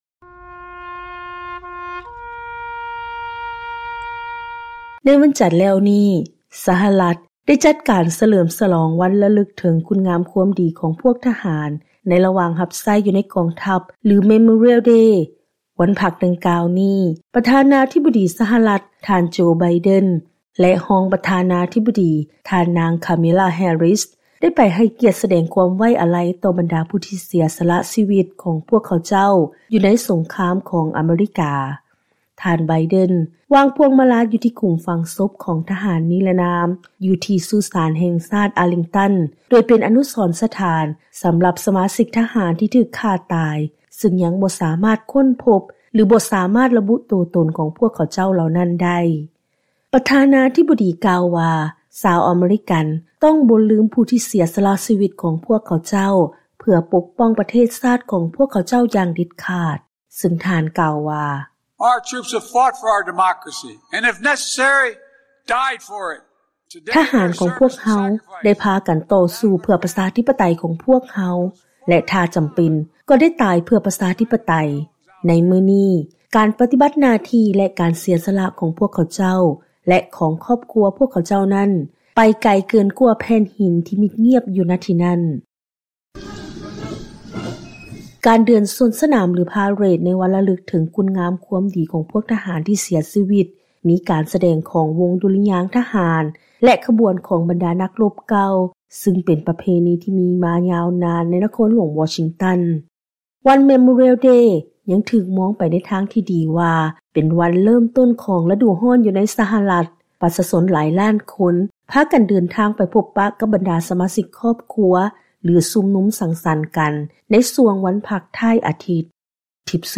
ເຊີນຟັງລາຍງານກ່ຽວກັບ ການສະແດງຄວາມໄວ້ອາໄລ ຕໍ່ຜູ້ທີ່ເສຍຊີວິດຢູ່ສົງຄາມ ໃນວັນພັກ Memorial Day ຂອງ ທ່ານໄບເດັນ ແລະ ທ່ານນາງແຮຣິສ